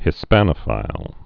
(hĭ-spănə-fīl)